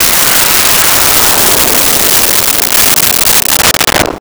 Missle 02
Missle 02.wav